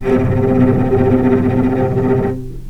vc_trm-C3-pp.aif